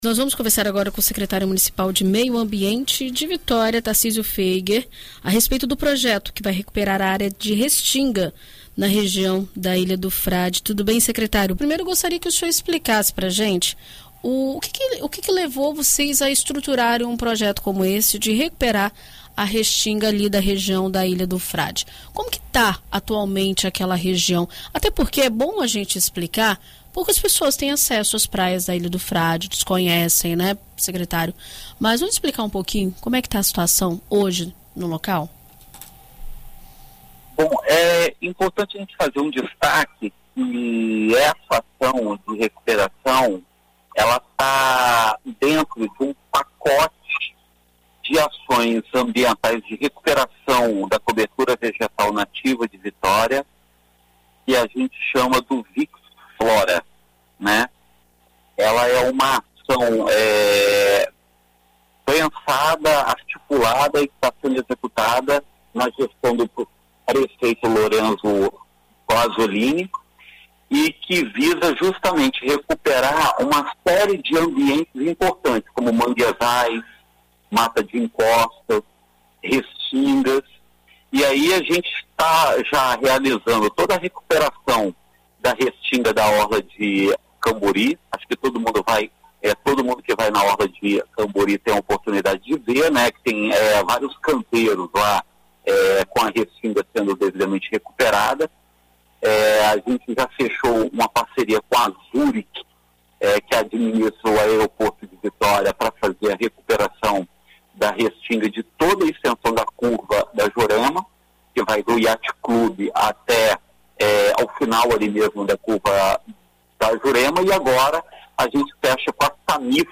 Em entrevista à BandNews FM Espírito Santo nesta terça-feira (16), o secretário municipal de Meio Ambiente, Tarcísio Foeger, explica a importância do projeto e como ele irá gerar impactos na cadeia de biodiversidade local, inclusive na proteção da Baía das Tartarugas, uma Área de Proteção Ambiental (APA).